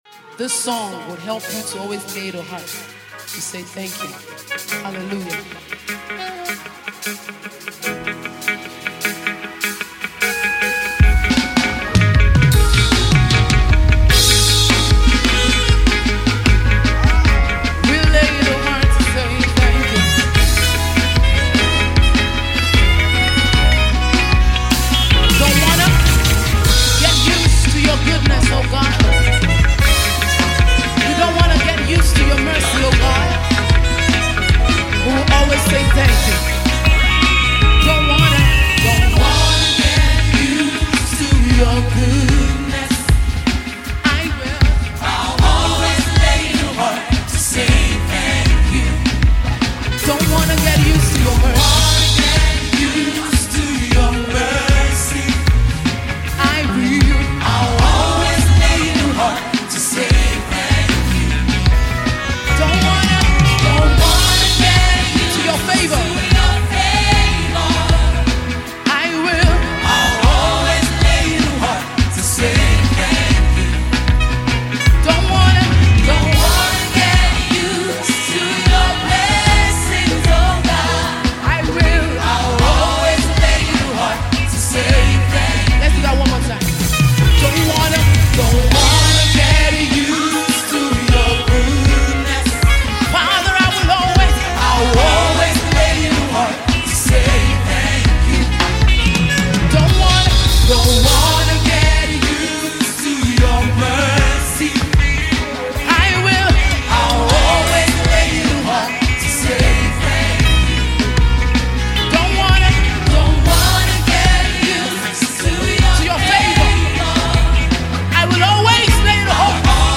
vocal powerhouse
Worship